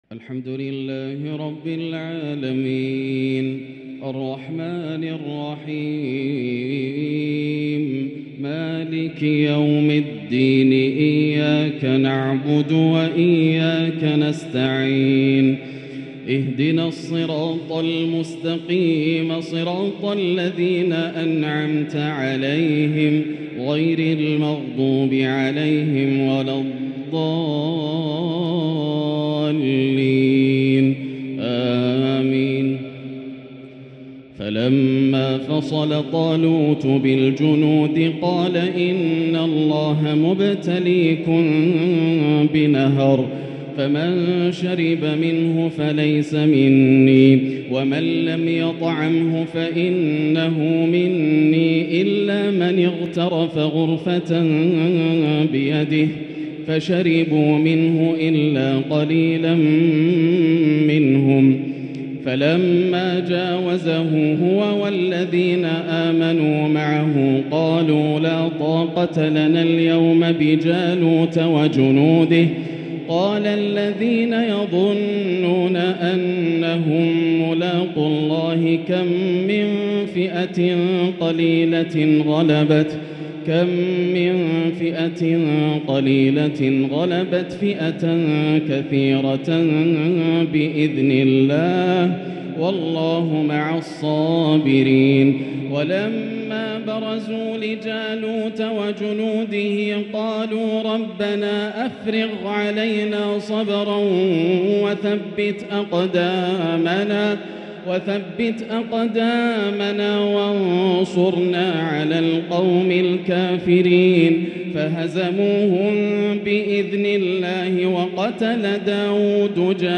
تراويح ليلة 3 رمضان 1444هـ من سورة البقرة (249-271) > الليالي الكاملة > رمضان 1444هـ > التراويح - تلاوات ياسر الدوسري